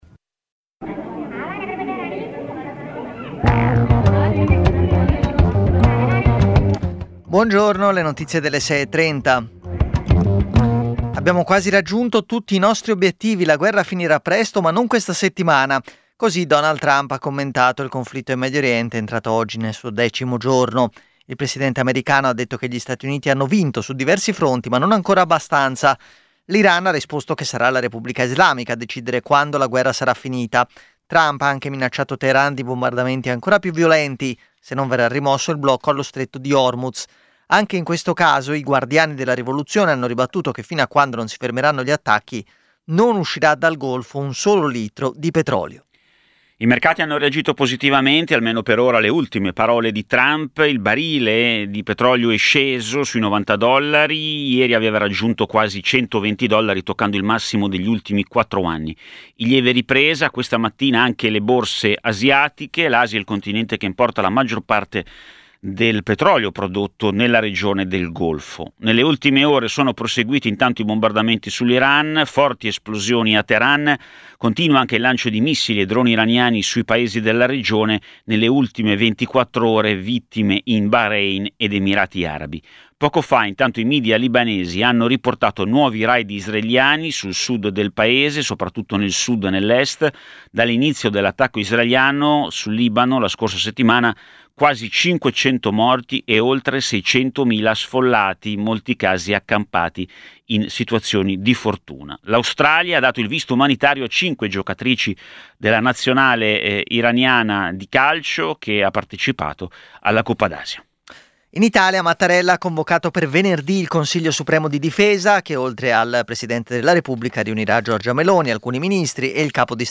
Edizione breve del notiziario di Radio Popolare.